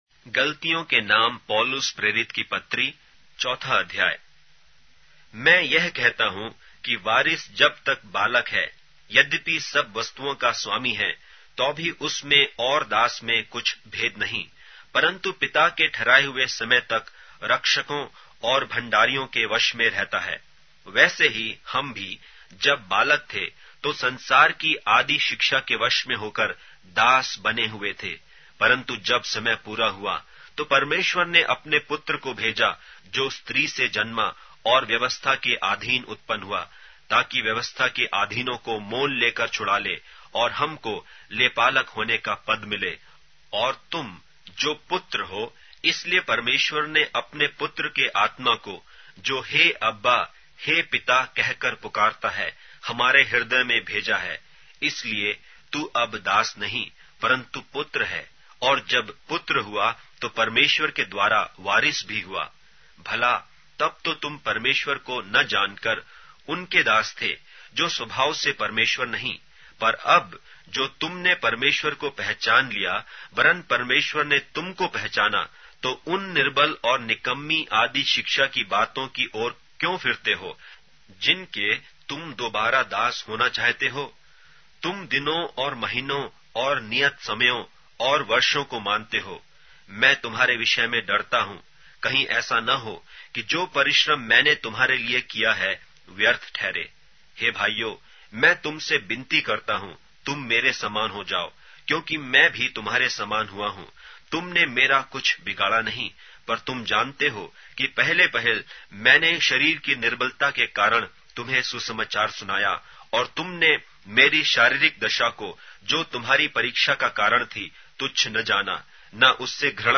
Hindi Audio Bible - Galatians 4 in Irvgu bible version